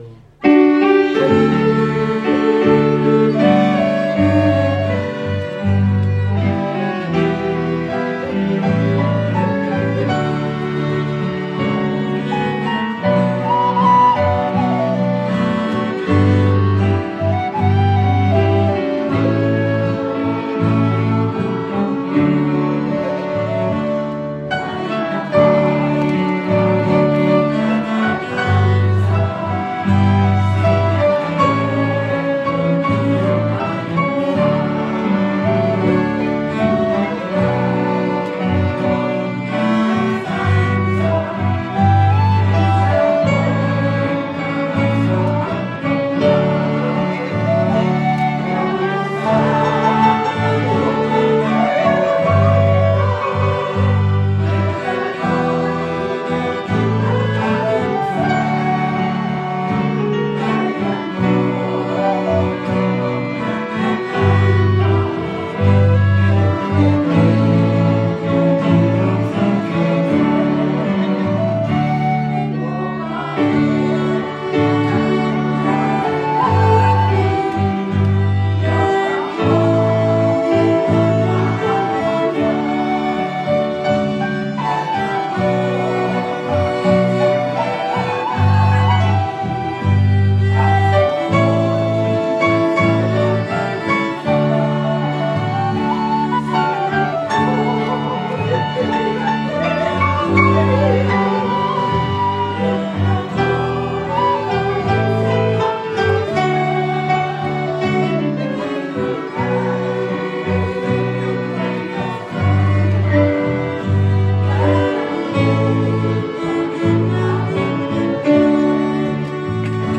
Ja tässä on otteita syksyn 2025 gospel-illoista: